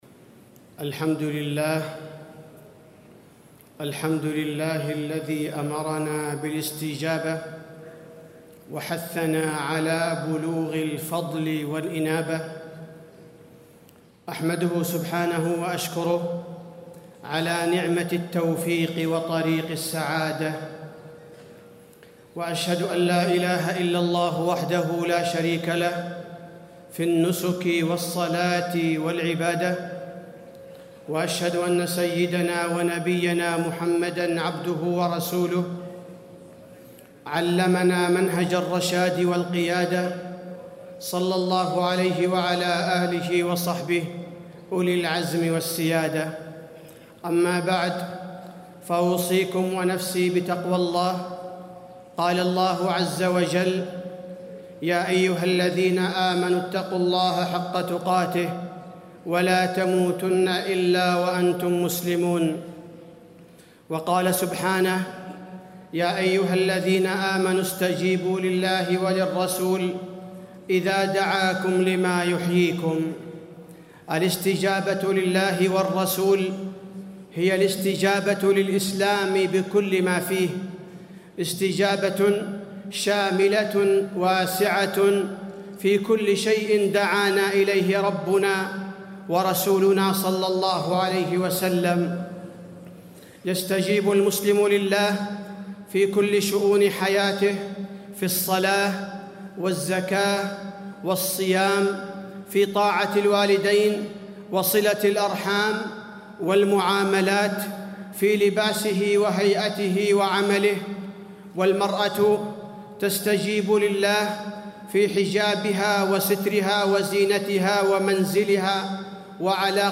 تاريخ النشر ٢١ ذو القعدة ١٤٣٤ هـ المكان: المسجد النبوي الشيخ: فضيلة الشيخ عبدالباري الثبيتي فضيلة الشيخ عبدالباري الثبيتي سرعة الاستجابة لله ورسوله